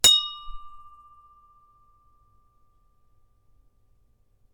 drumstick holder ding
ding drumstick holder metal ting sound effect free sound royalty free Sound Effects